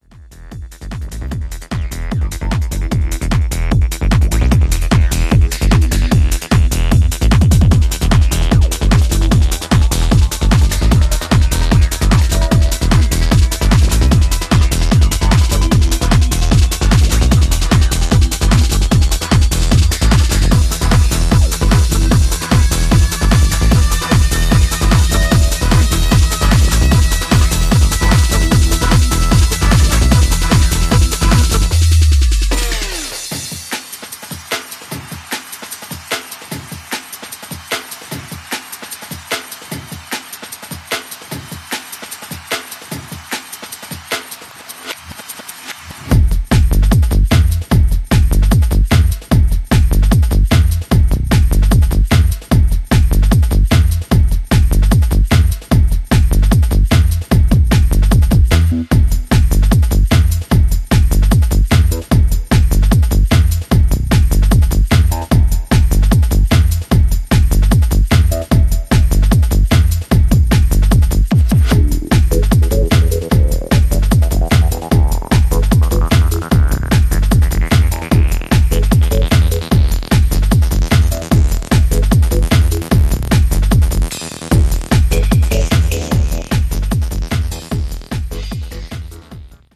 90sプログレッシヴ・ハウス/トランスの影響を昇華した極彩色の楽曲群を展開